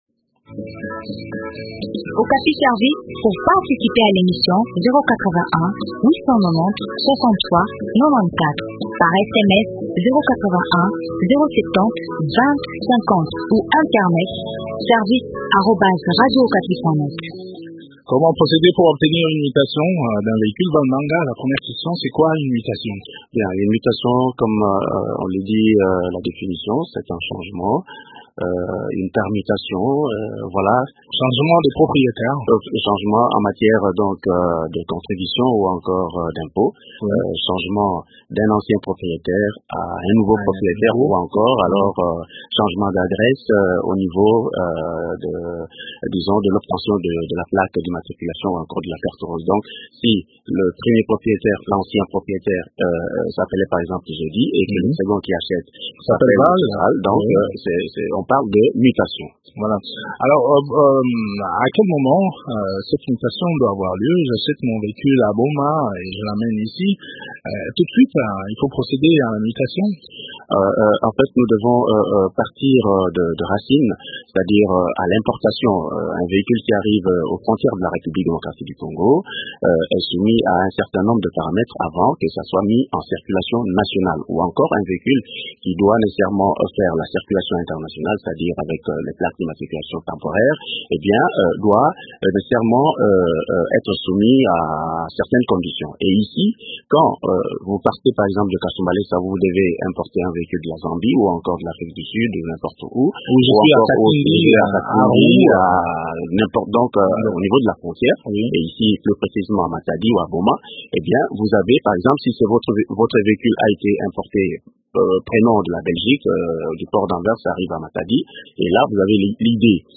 Il explique la procédure à suivre dans cet entretien